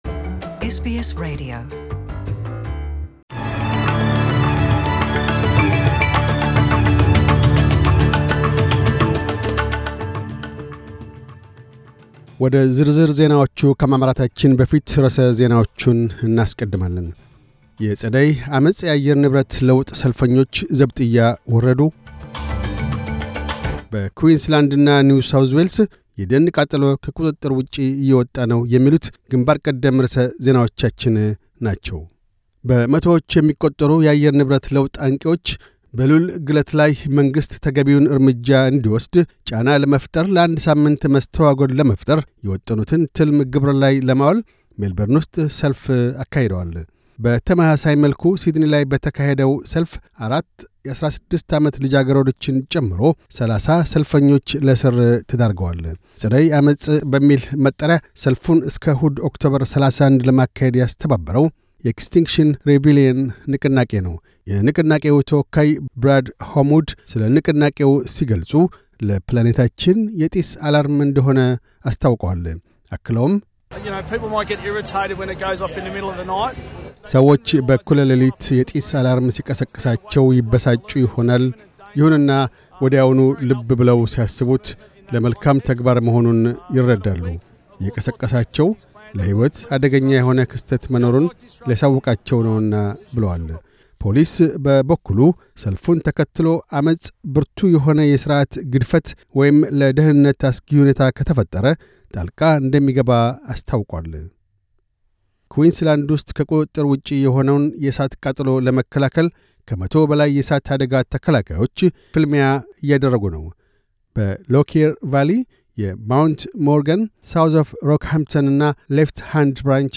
News Bulletin 0710